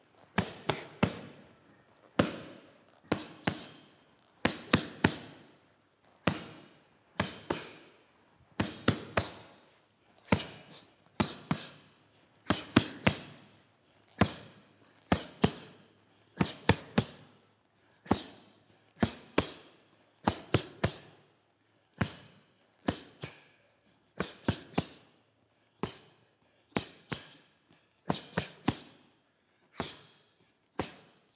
For videos featuring trainer and trainee, this effect covers an essential base in any boxer’s regimen. The punches pop and synthesize with the fighter’s quick, sharp breaths.